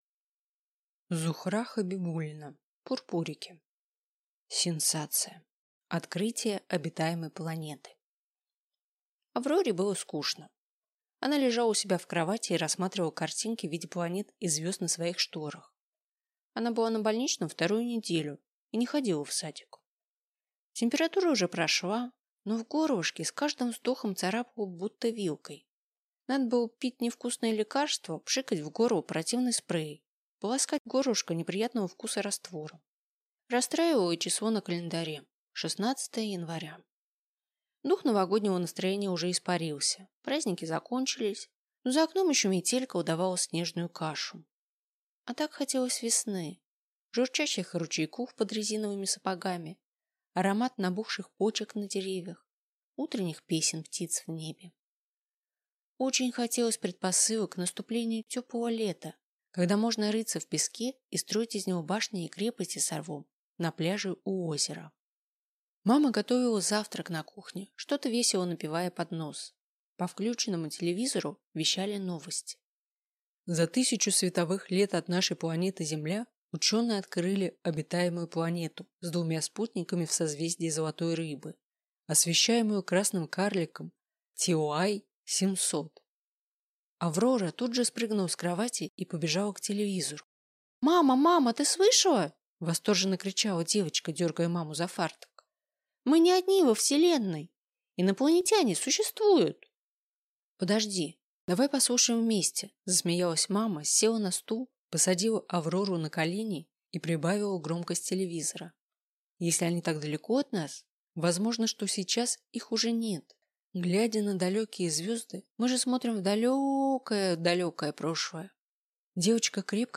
Аудиокнига Пурпурики | Библиотека аудиокниг